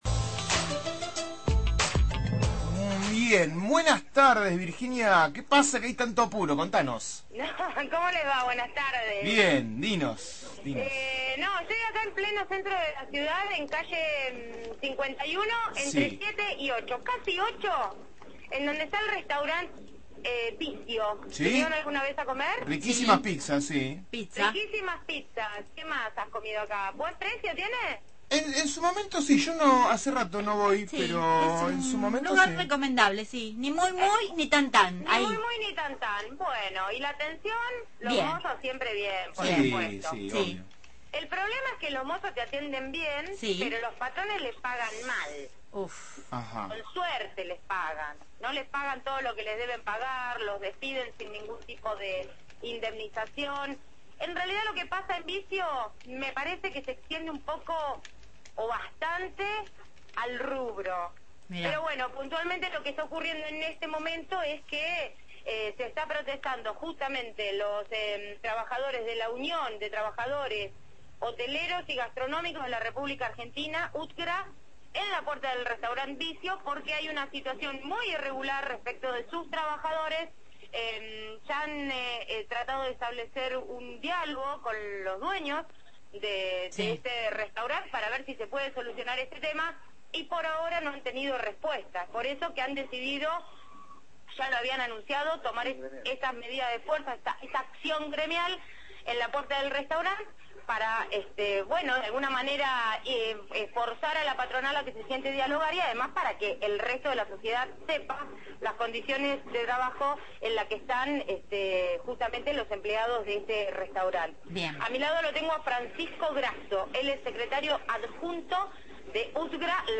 con el escrache de la Unión de Trabajadores del Turismo, Hoteleros y Gastronómicos (U.T.H.G.R.A.) seccional La Plata al local Vizio, ubicado en 51 esquina 8, para denunciar que la empresa liquida los sueldos de los trabajadores en forma irregular, con un mayor porcentaje en negro que en blanco, y cinco despidos irregulares.